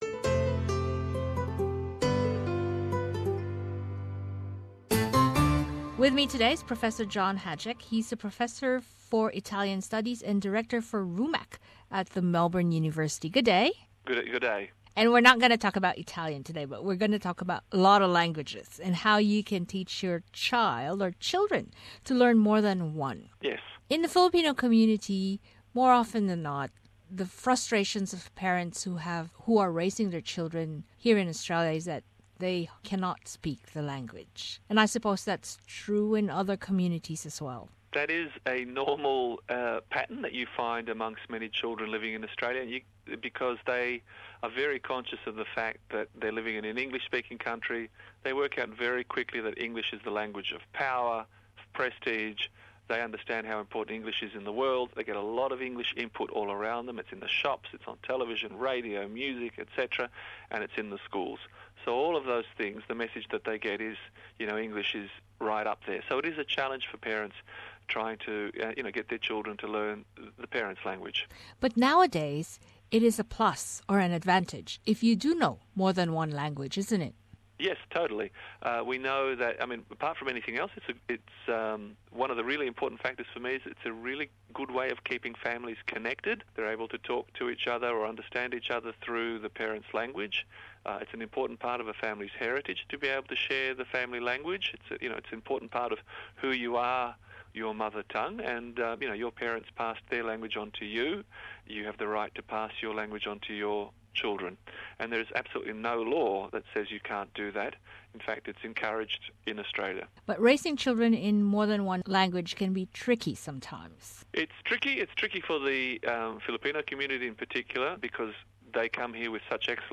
Thinking of teaching your children to speak in Filipino? A language expert says, the best way to start teaching them is through our everyday conversations.